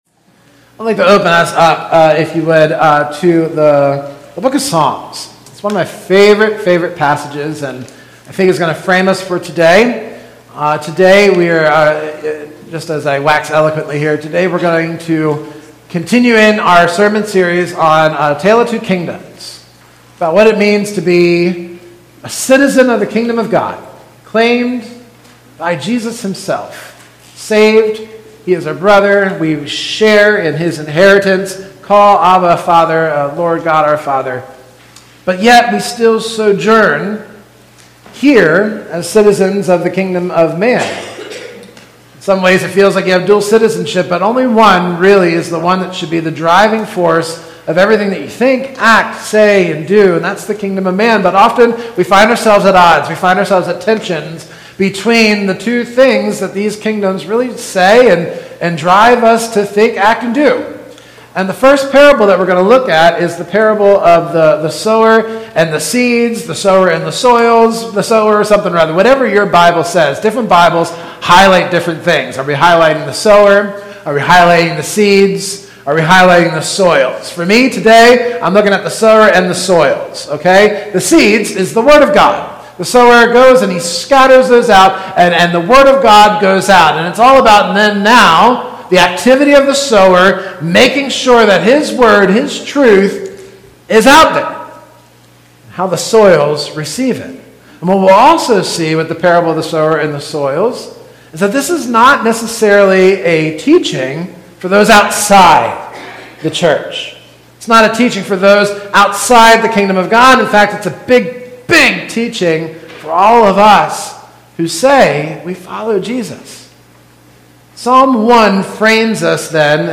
In this sermon series, we will explore Jesus’ Kingdom parables to learn what this new citizenship means for our faith, our loyalties, and our daily lives, and how Christ sends us into the world to bear witness to His Kingdom—freeing us from the idols, false promises, and misplaced hopes of the kingdom of man.